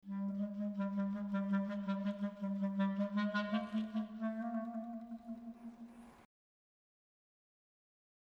Drake Mabry, another American composer, adopts the use of quarter-tones for several musical purposes in his Street Cries (1983) for solo clarinet.
The very dark quality of the quarter-tones in measures 8 and 9 adds another dimension to the conflicting patterns of pitch and articulation (Example #55).